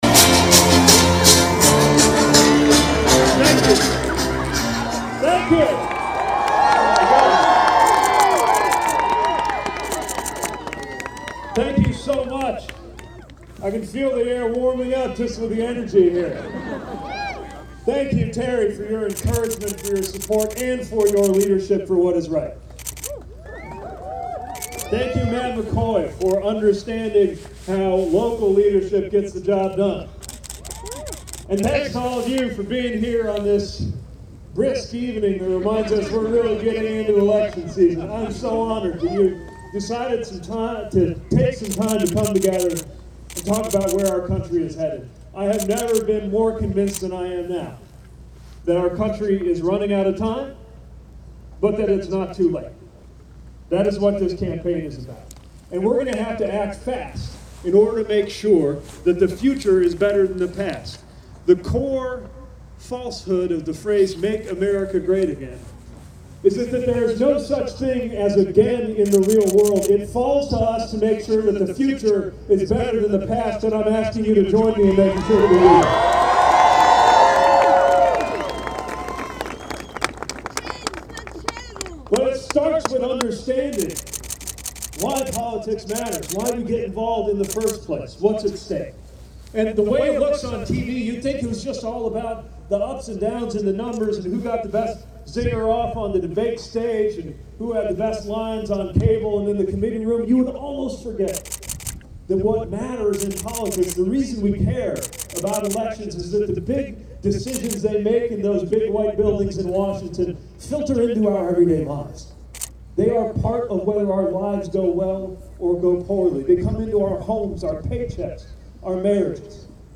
South Bend, Indiana Mayor Pete Buttigieg (D) continued his presidential campaign in Iowa with an outdoor town hall on the grounds of Roosevelt High School in Des Moines last night. The open event, with temperatures dropping into the low forties toward the end, had an audience of several hundred.
In addition to the seasonally cold temperature there was a steady wind throughout the event.